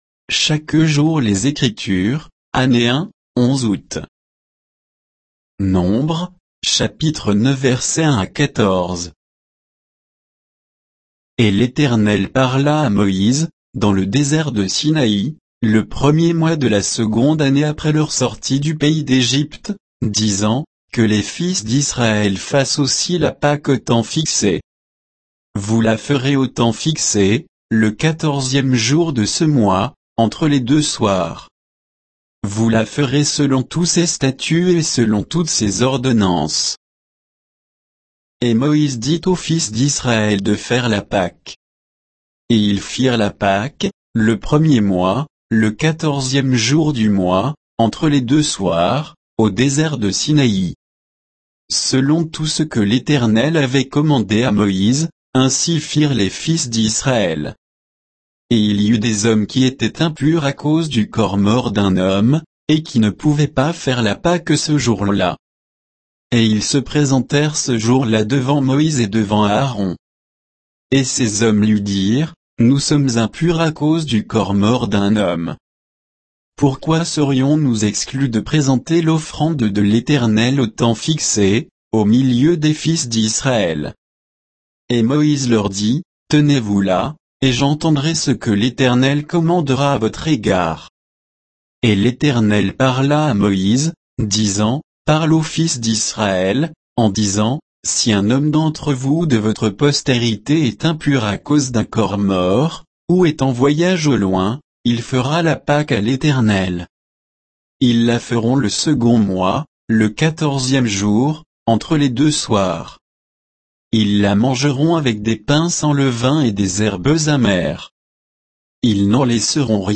Méditation quoditienne de Chaque jour les Écritures sur Nombres 9